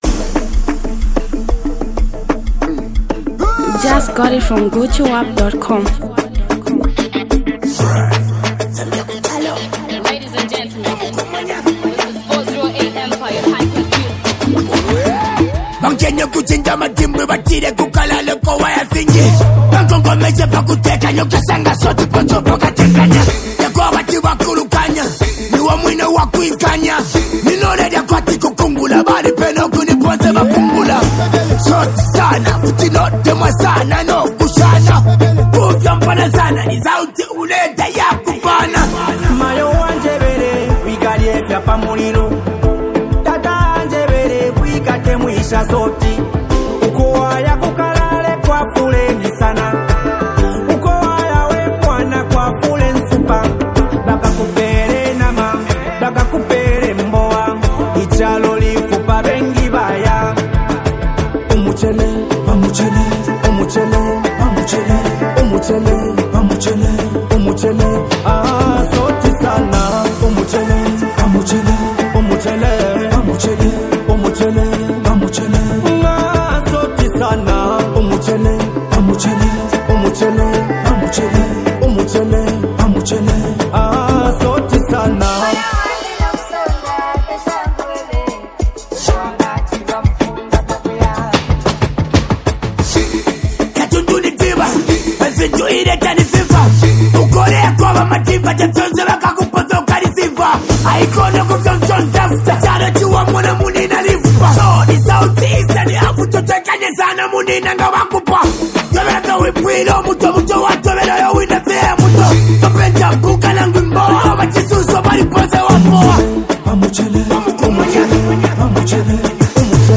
kalindula style.